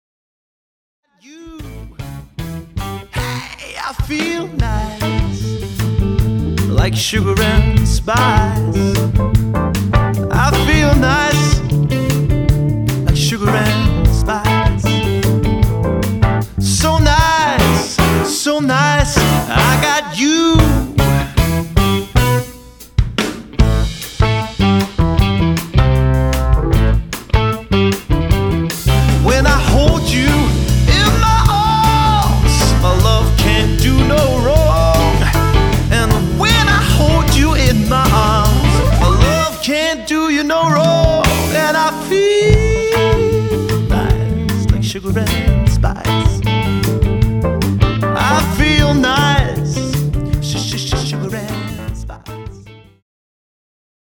Toptjekket fempersoners partyband.
• Coverband